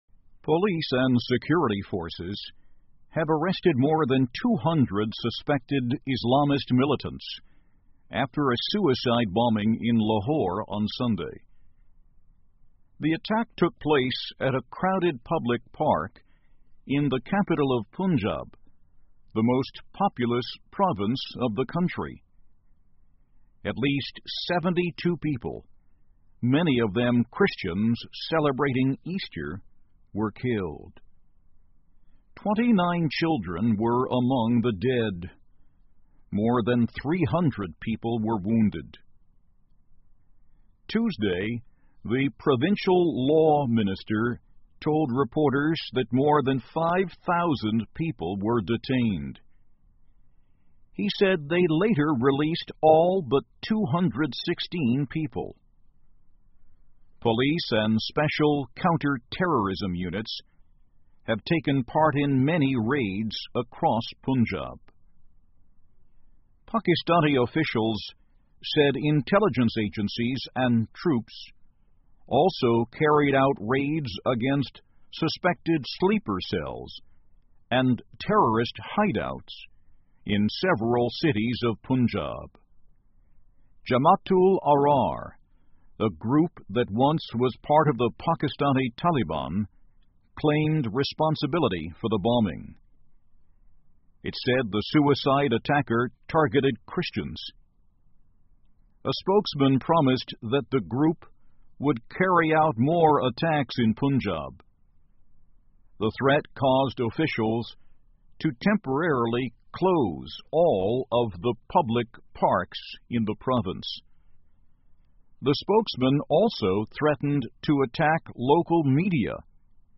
VOA慢速英语--巴基斯坦拘捕200余名疑似武装分子 听力文件下载—在线英语听力室